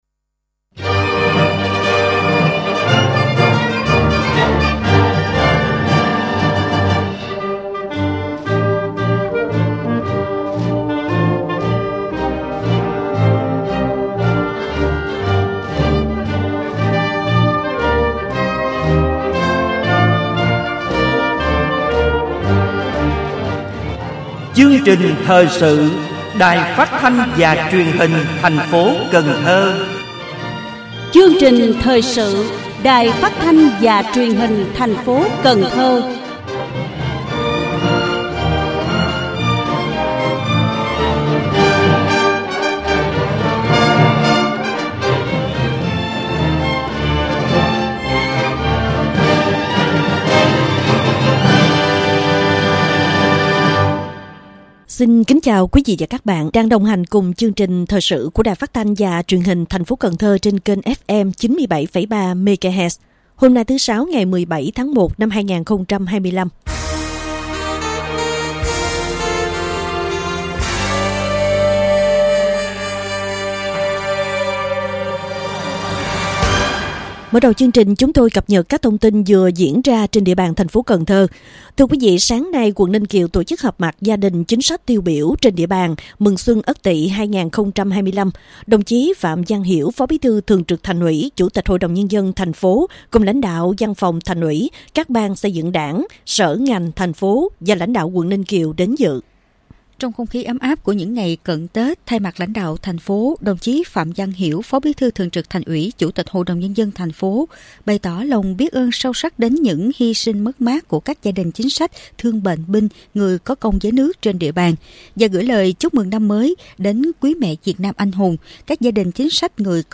Thời sự phát thanh trưa 17/1/2025
Mời quý thính giả nghe chương trình Thời sự phát thanh trưa của Đài Phát thanh và Truyền hình TP. Cần Thơ.